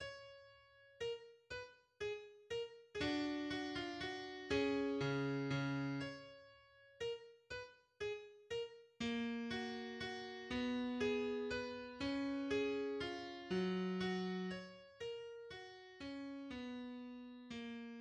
Genre Symphonie
Menuet (Allegretto), à
, en fa # majeur
Première reprise du Menuet (Allegretto) :